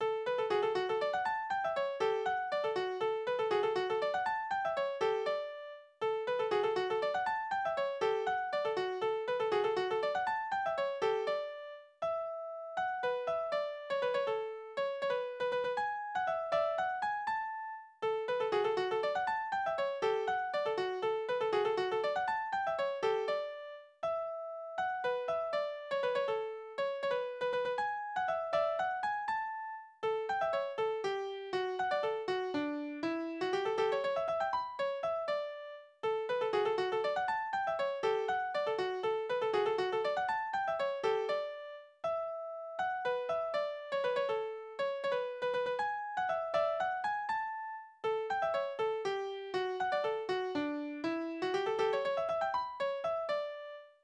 « 10919 » Kußquadrille Tanzverse: Kussquadrille Tonart: D-Dur, A-Dur Taktart: 3/8 Tonumfang: große Sexte Besetzung: instrumental Anmerkung: Vortragsbezeichnung: bewegt Die Tonart wechselt im Mittelteil zu A-Dur.